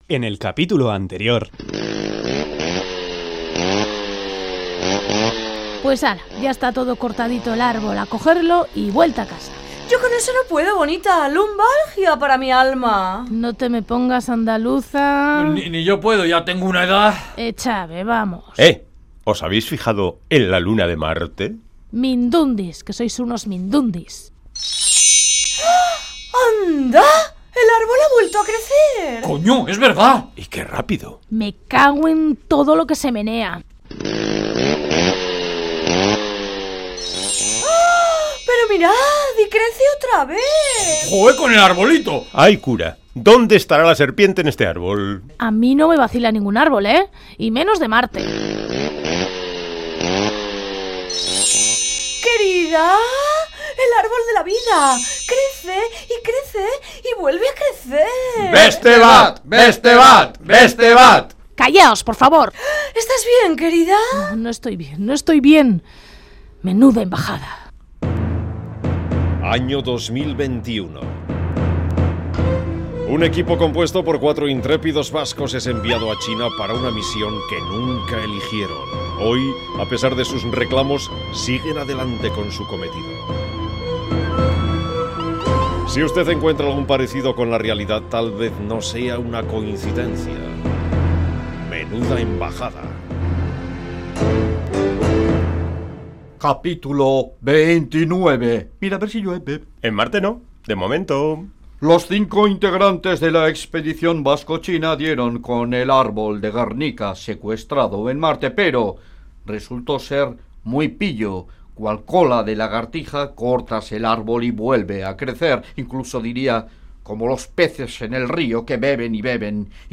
Radio Euskadi RADIONOVELA MENUDA EMBAJADA: CAPÍTULO 29 Última actualización: 20/05/2016 13:18 (UTC+2) Una expedición vasco-china tiene como objetivo abrir la primera embajda de Euskadi en el mundo, en China.